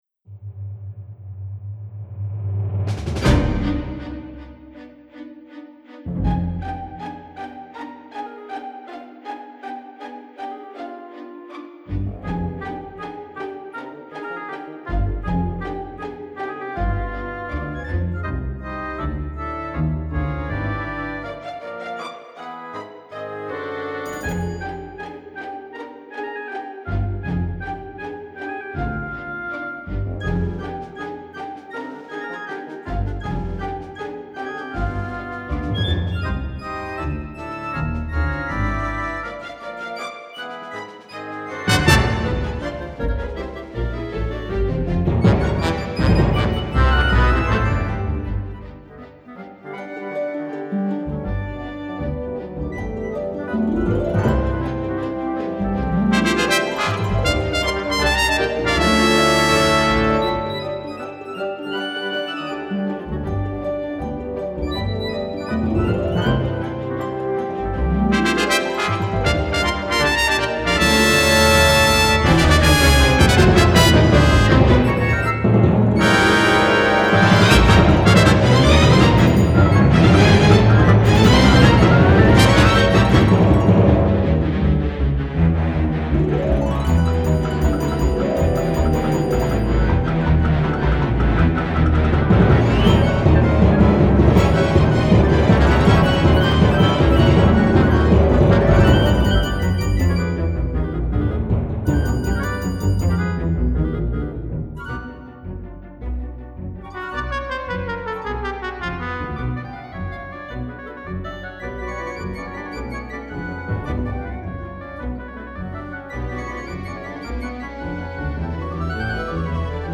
SYMPHONIC PROJECT FOR CHILDREN :
Część pierwsza, zdecydowanie w tradycyjnym stylu.
First movement is very traditional.
* - temporary demo version will be replaced very very soon :) & followed by other movements.